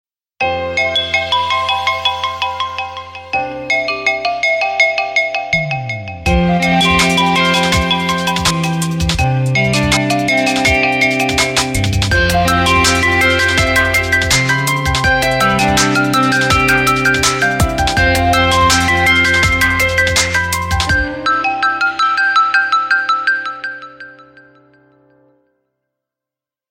громкие
без слов
красивая мелодия
Electronica
колокольчики
ксилофон